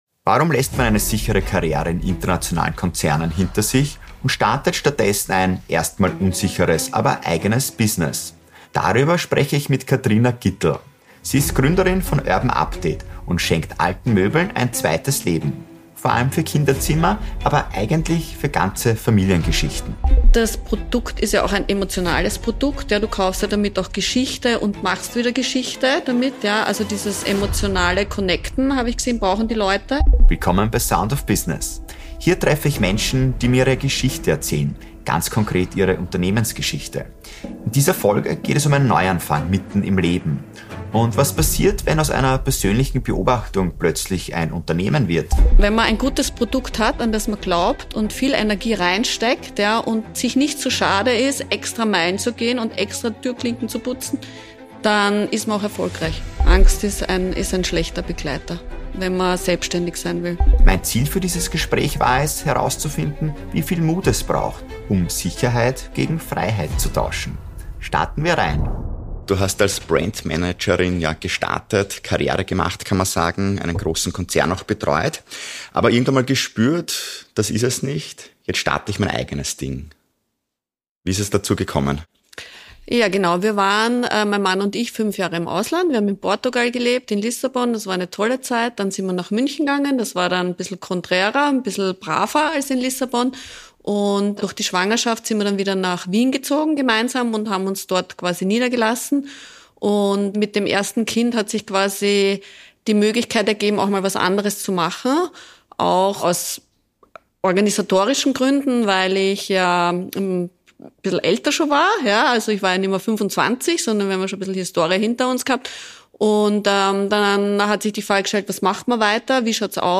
Heute im Gespräch